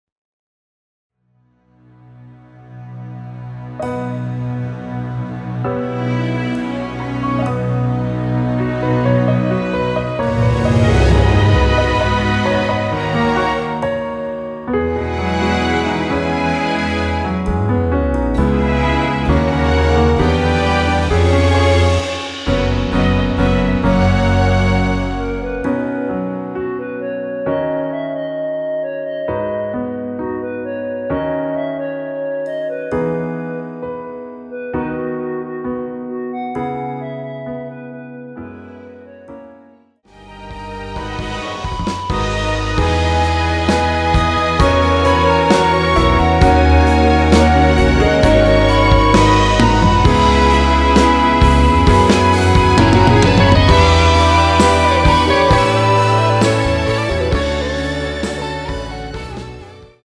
원키에서(+2)올린 멜로디 포함된 MR입니다.
멜로디 MR이라고 합니다.
앞부분30초, 뒷부분30초씩 편집해서 올려 드리고 있습니다.
중간에 음이 끈어지고 다시 나오는 이유는